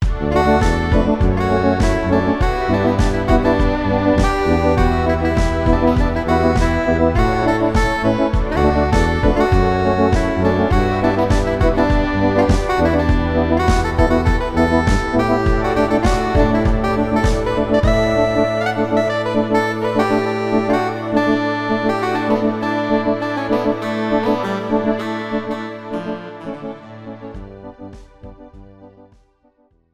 This is an instrumental backing track cover.
• Key – D
• Without Backing Vocals
• No Fade